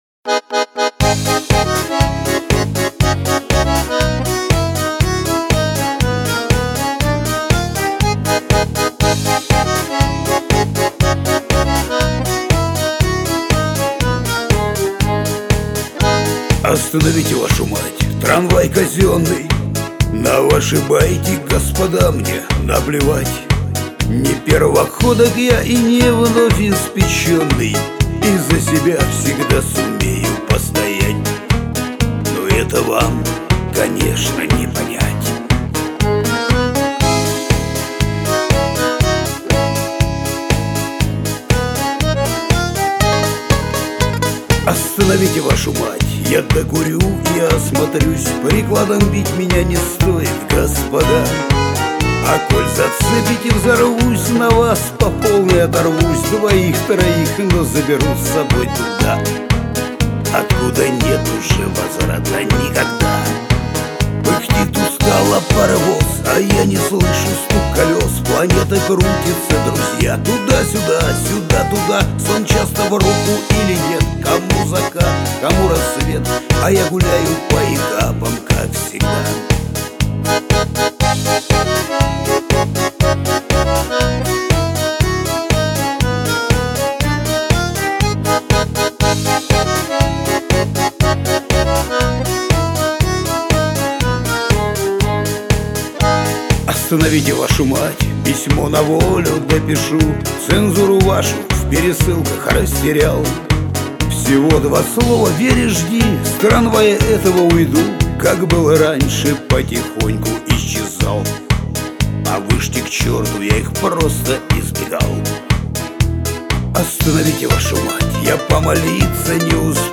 Жанр: Шансон Формат